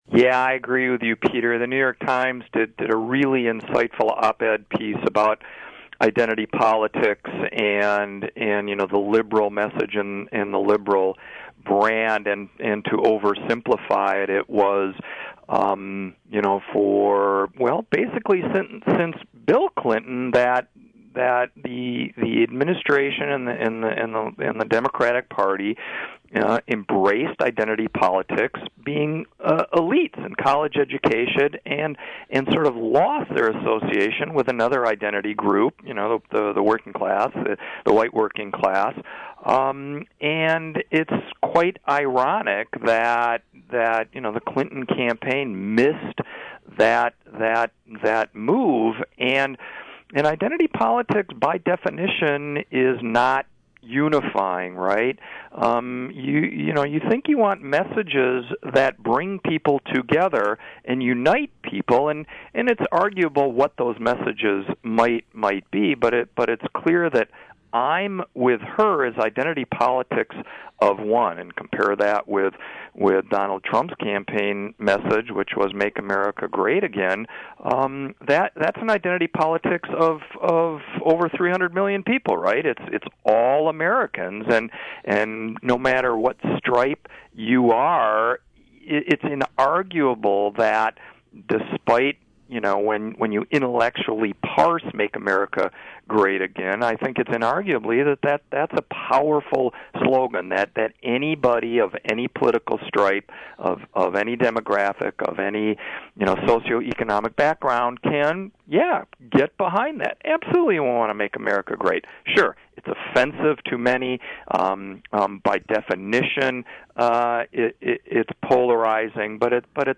In-Depth Interview: Naming Expert Says Clinton’s Slogan “I’m With Her” Was Most Tone-Deaf Slogan Ever